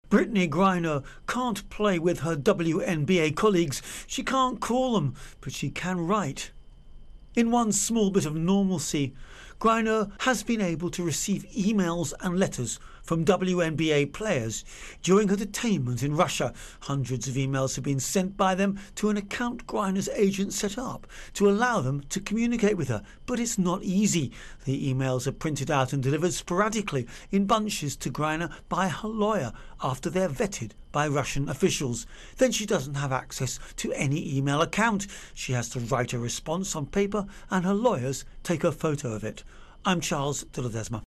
Russia Brittney Griner Emails Intro and Voicer